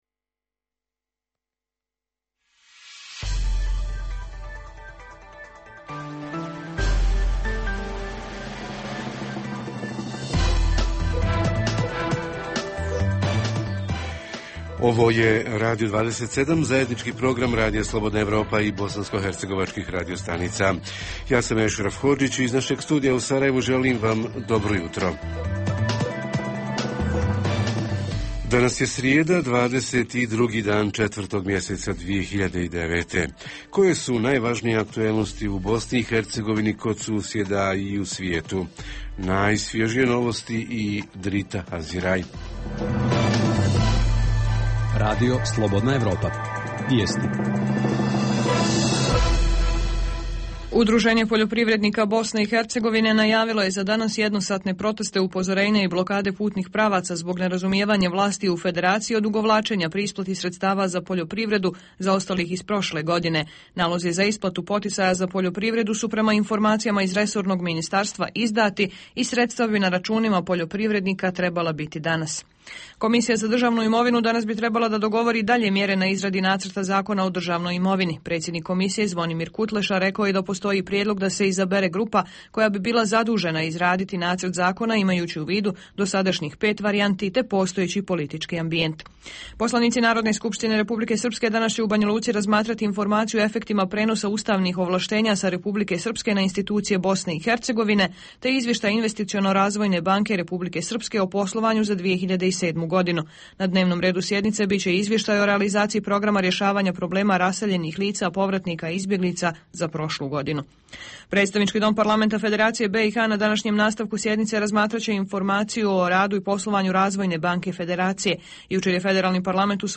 Jutarnji program za BiH koji se emituje uživo. Tema jutra: vozni park vaše/naše lokalne samouprave (općina ili grada). Reporteri iz cijele BiH javljaju o najaktuelnijim događajima u njihovim sredinama.